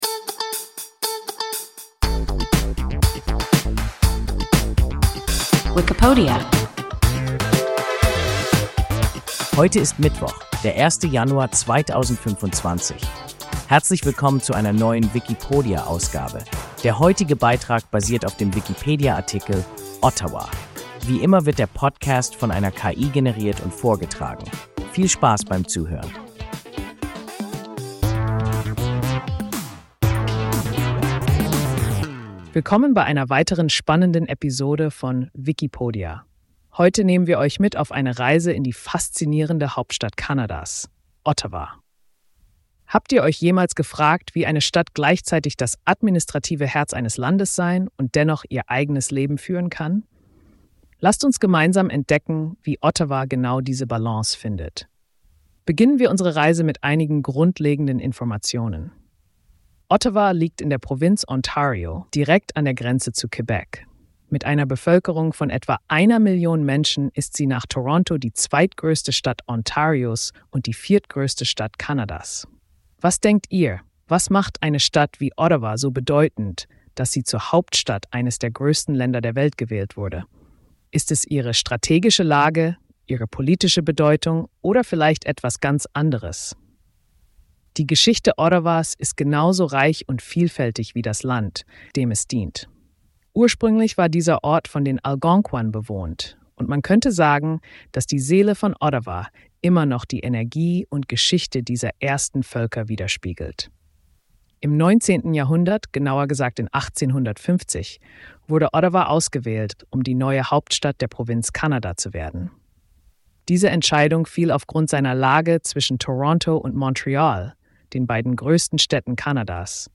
Ottawa – WIKIPODIA – ein KI Podcast